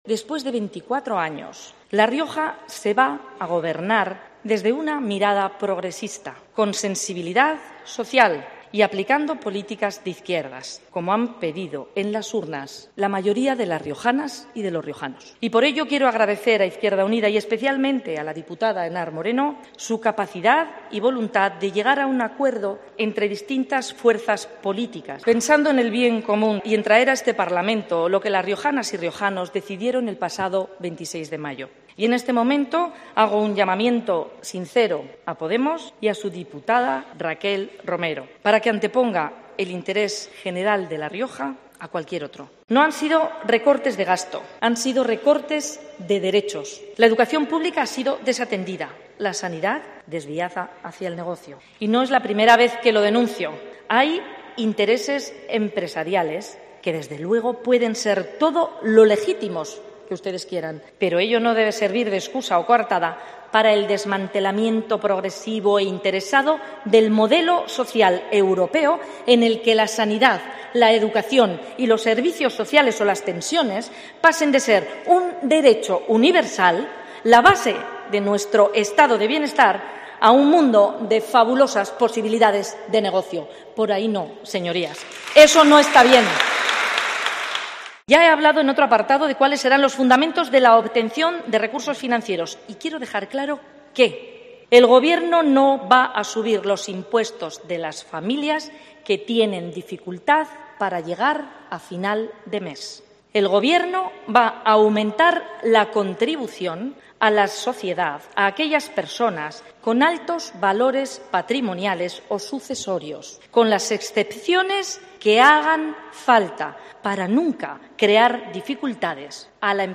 DEBATE INVESTIDURA
Te adjuntamos en esta noticia el contenido íntegro de la intervención de la candidata socialista a la Presidencia de La Rioja.